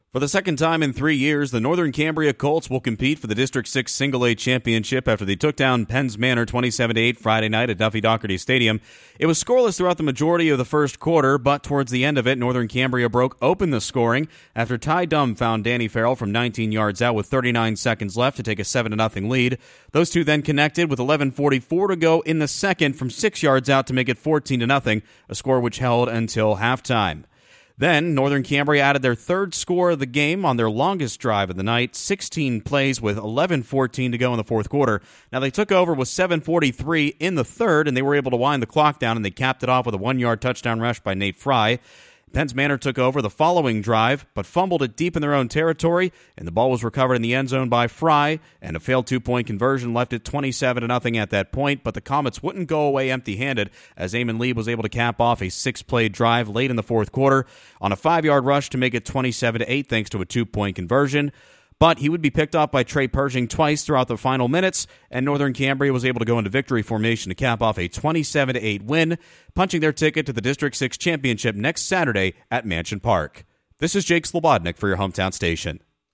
nc-pm-district-6-semifinal-wrap.mp3